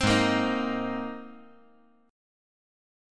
Abadd9 Chord
Listen to Abadd9 strummed